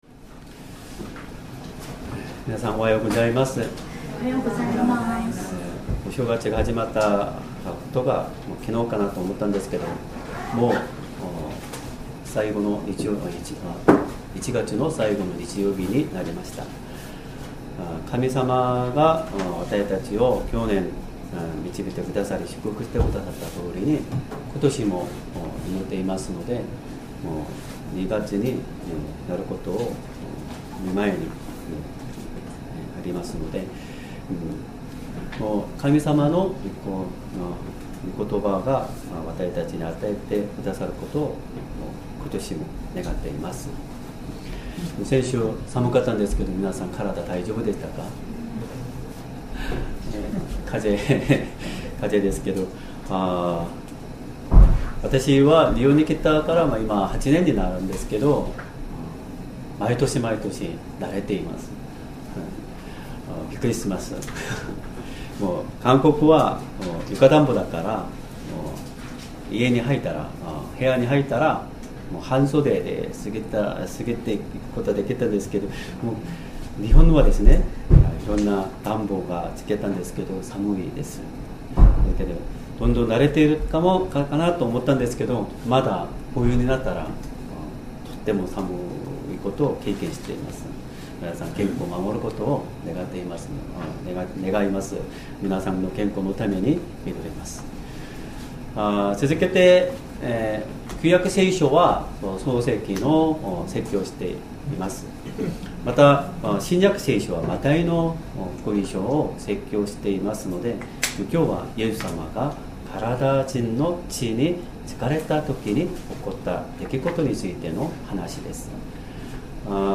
Sermon
2026年1月25日 主日礼拝 説教 「悪霊につかれた人を直してくださったイエス様 」 聖書 マタイの福音書 8章 28 - 34節 8:23 それからイエスが舟に乗られると、弟子たちも従った。